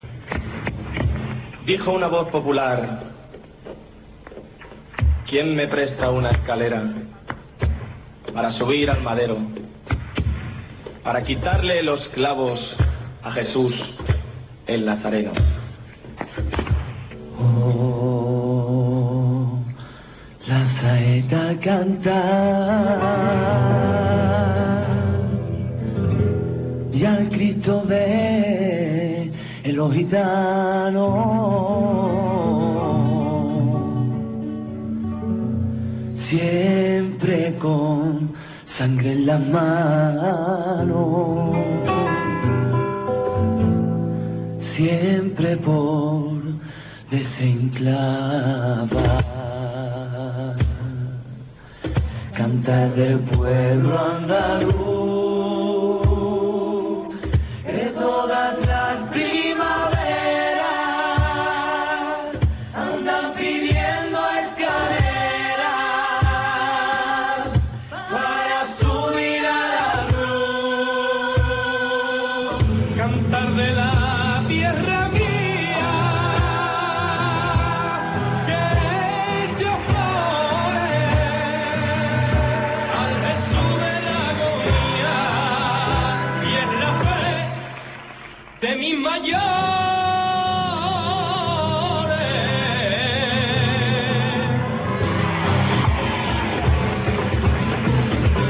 Quitar los clavos de la Cruz. Saeta.